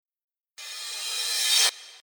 リバースシンバル（シューーーーーーーーーーッ↑っていう音。）
これはリバースシンバルっていう音なんですね。
アレをリバース（逆再生）してる音なんです。